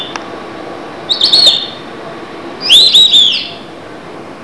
siriri.wav